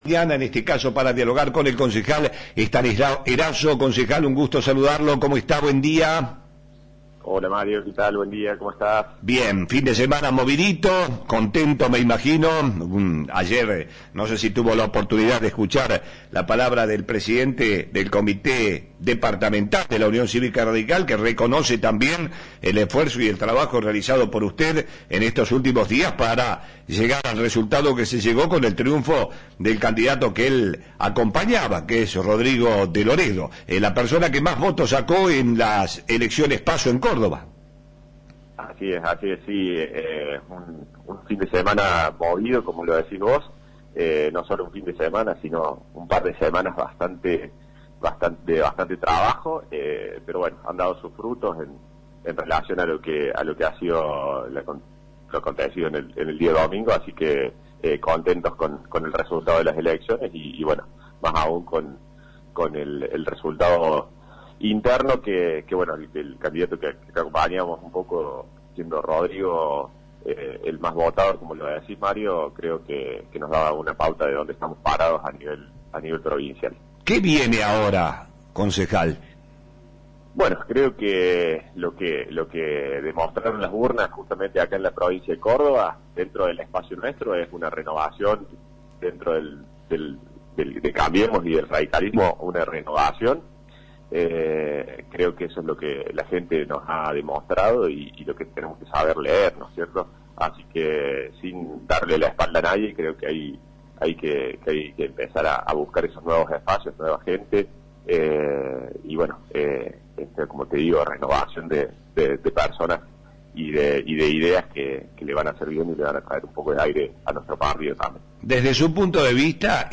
HABLÓ EL CONCEJAL ESTANISLAO ERASO. - Flash FM 107.7